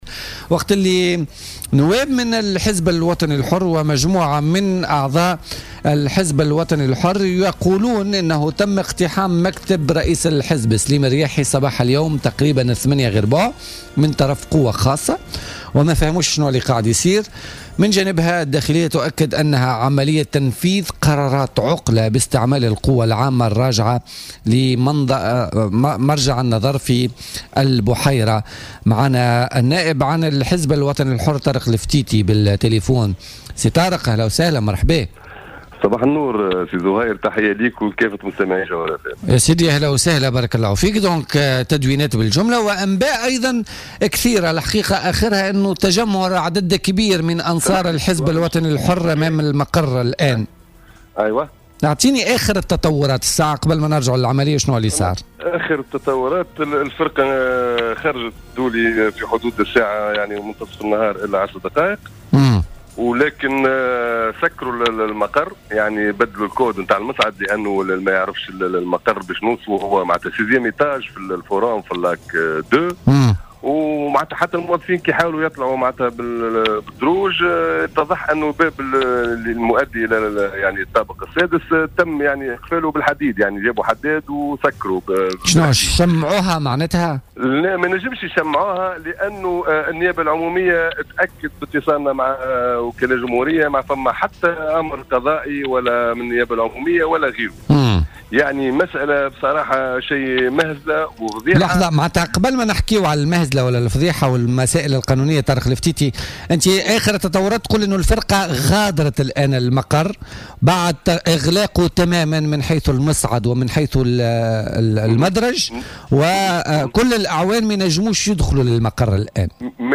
وأضاف في اتصال هاتفي بـ "الجوهرة أف أم" من خلال برنامج "بوليتيكا" أنه تم الاتصال بوكيل الجمهورية الذي أكد لهم أنه لا يوجد أي أمر قضائي بشأن العقلة التي صرحت بشأنها وزارة الداخلية .